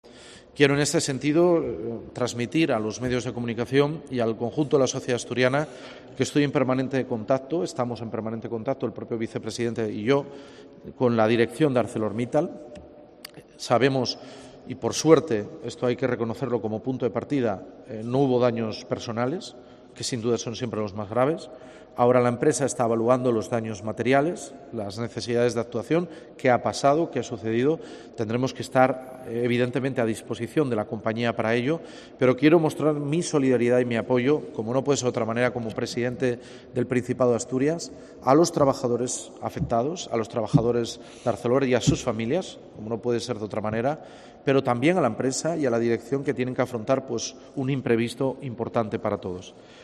Así lo ha indicado, en declaraciones a los medios de comunicación, durante la visita a la nueva planta de clasificación de la basura bruta de Cogersa.